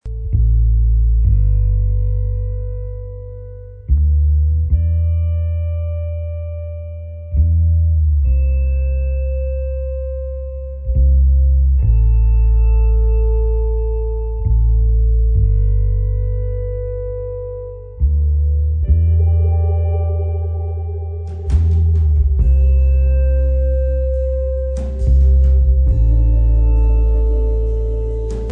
Post rock ed Ambient.